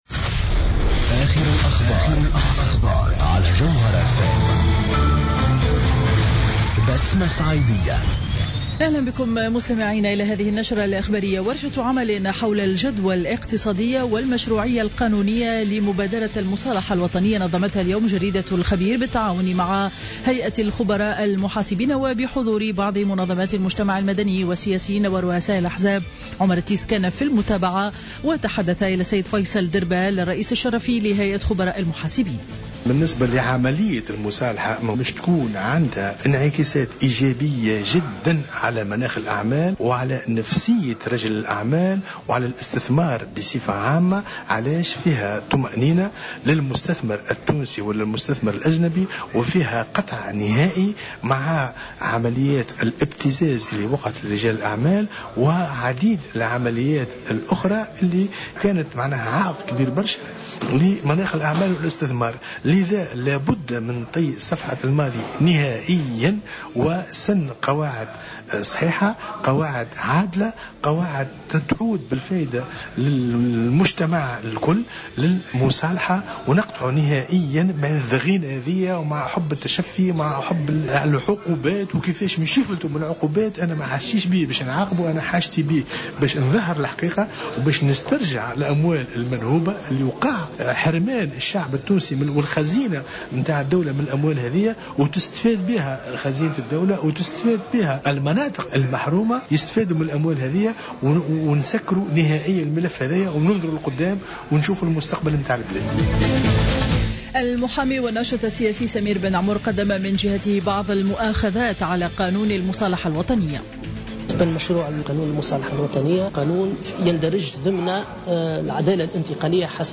نشرة أخبار منتصف النهار ليوم الجمعة 21 أوت 2015